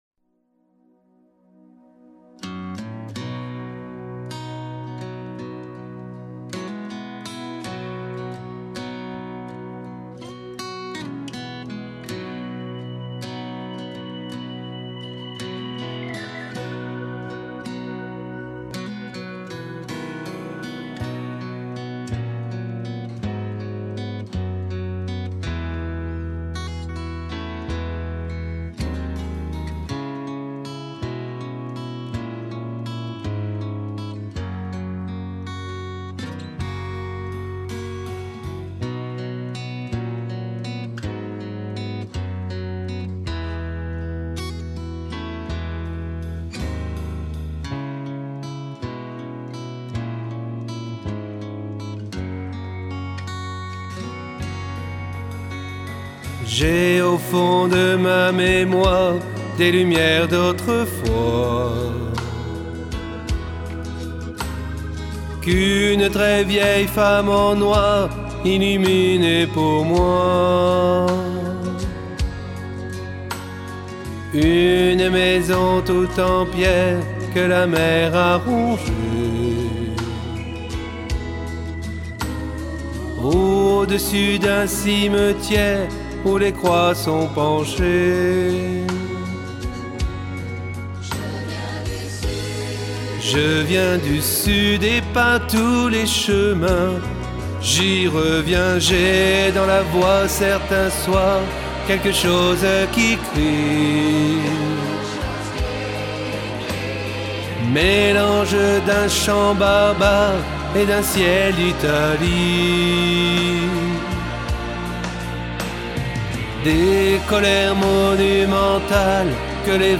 Basse